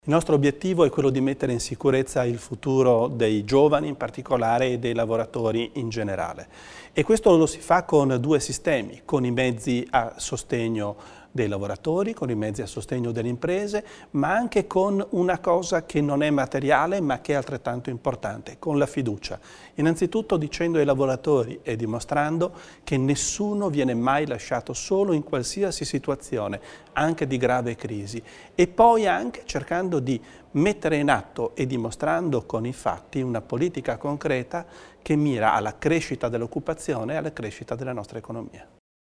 L'Assessore Roberto Bizzo illustra le strategie per garantire l'occupazione in Alto Adige